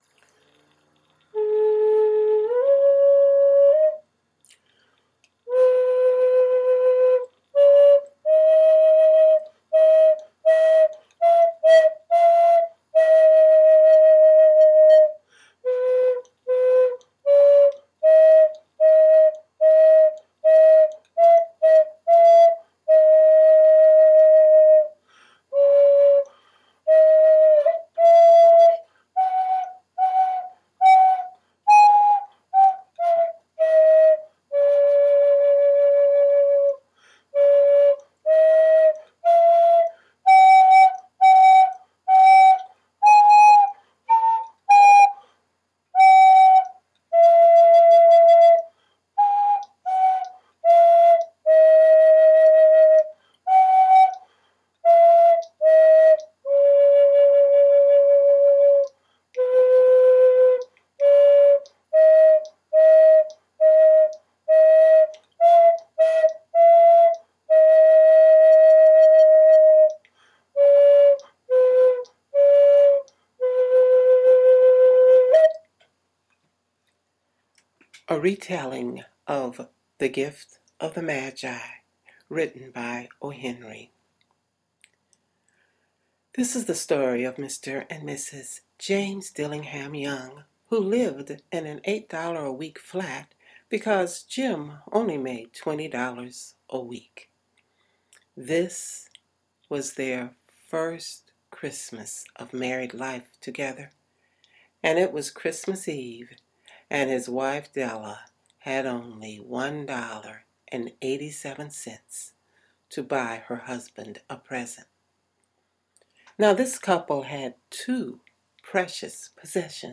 Holiday Gift-for-You.mp3 Stories and music Brighten holiday season Family delight